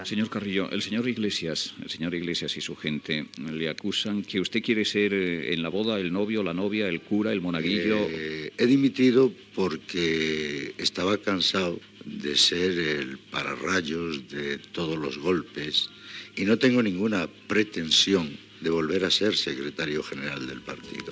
Fragment d'una entrevista a Santiago Carrillo, després de la seva dimissió com a secretari general del Partido Comunista de España
Info-entreteniment